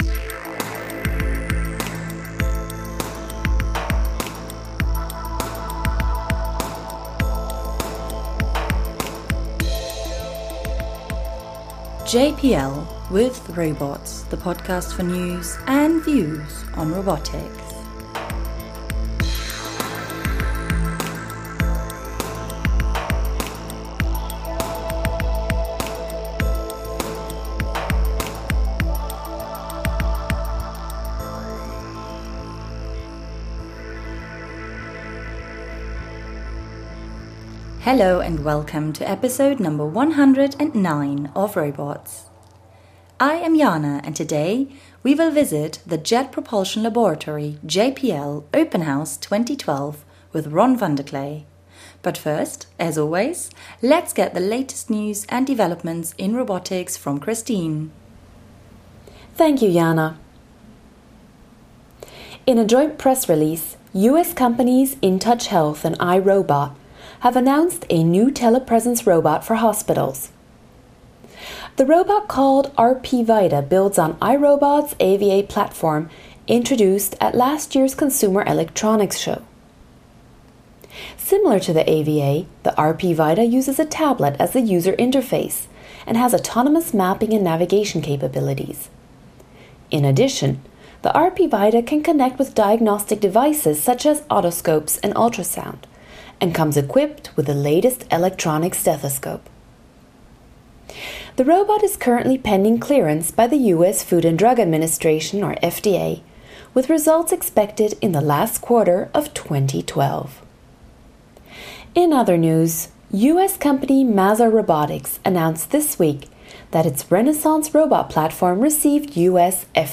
In today’s show we bring you to NASA’s Jet Propulsion Laboratory (JPL) Open House, just in time to prepare for the landing of the Curiosity Mars Rover in less than 10 days.
As we walk you through the JPL mission control center, clean rooms and facilities, we stop to chat with JPL engineers and developers about the many space systems we encounter including the rovers Athlete , Spirit & Opportunity and the Mars Reconnaissance Orbiter .